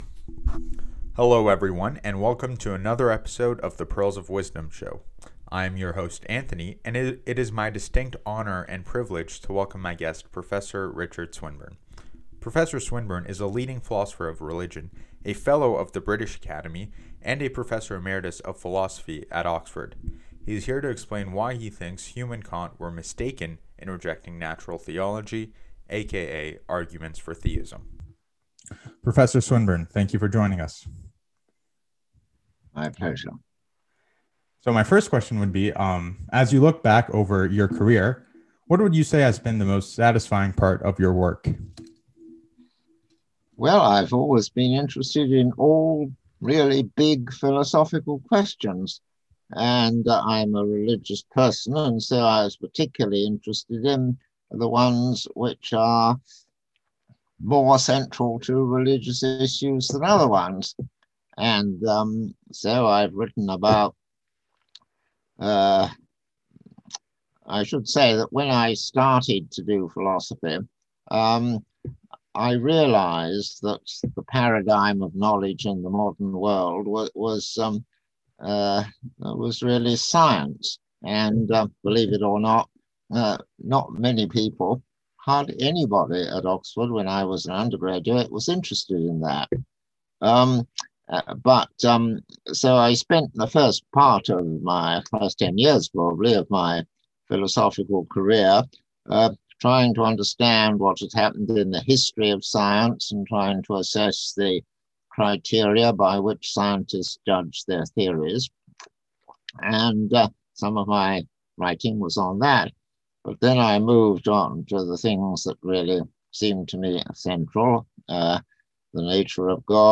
David Hume (1711-1776) and Immanuel Kant (1724-1804) were Enlightenment philosophers who sought to place constraints upon human cognition, and are thought by many to have discredited traditional argument for theism or natural theology. In this interview, Prof. Swinburne, a leading analytic philosopher of religion, a Fellow of the British Academy, and an emeritus professor of philosophy at Oxford, explicates and evaluates the arguments put forth by Hume and Kant.